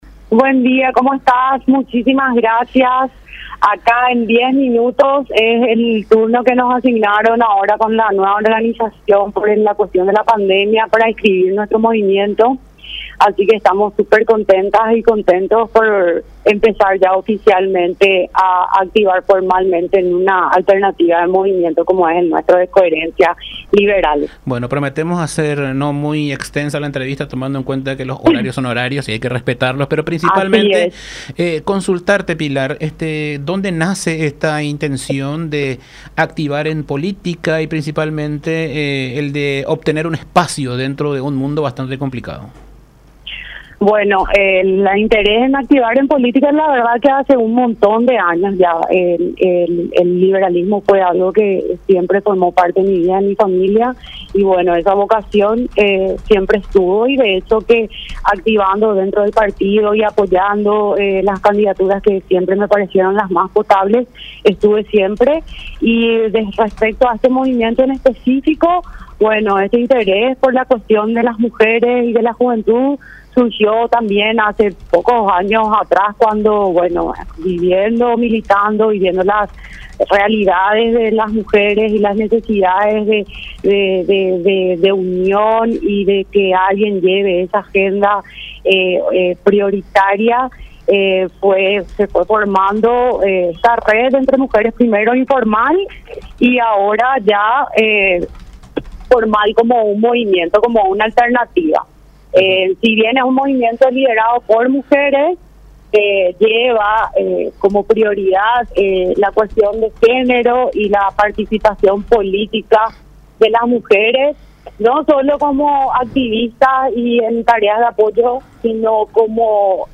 en diálogo con La Unión R800 AM